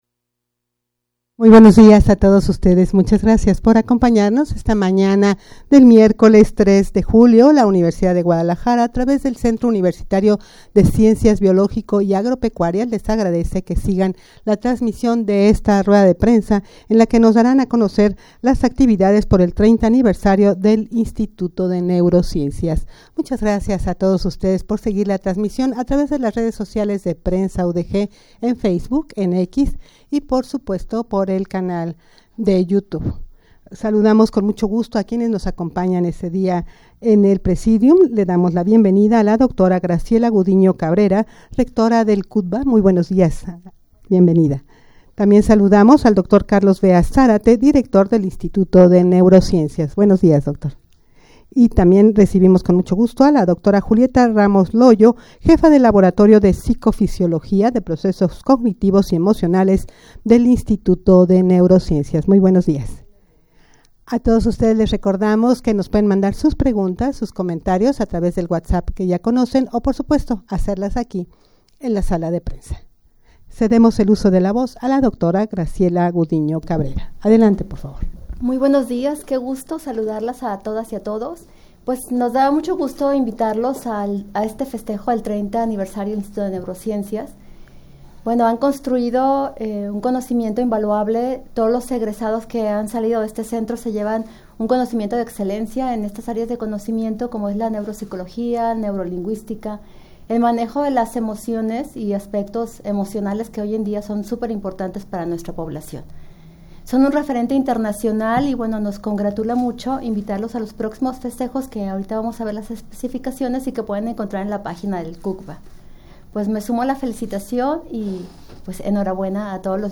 Audio de la Rueda de Prensa
rueda-de-prensa-para-conocer-las-actividades-por-el-30-aniversario-del-instituto-de-neurociencias-.mp3